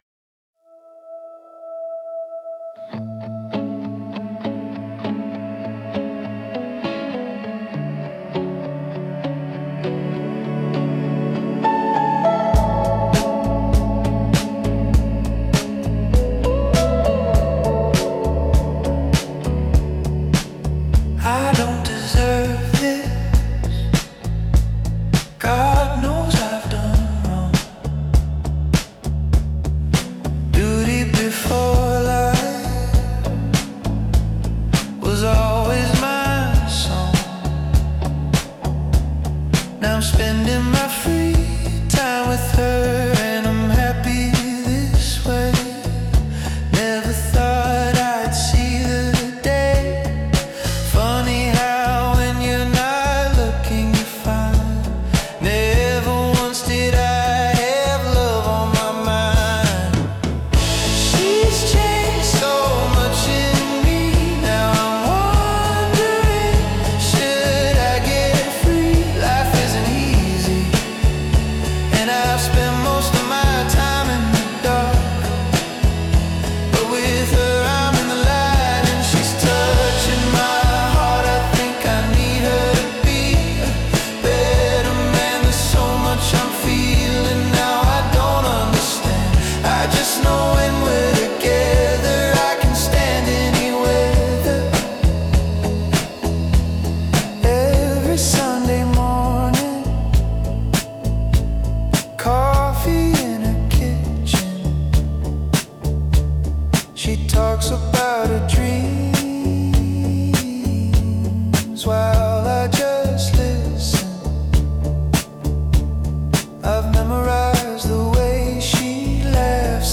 Indie Rock • Emotional Growth • Redemption • Intimate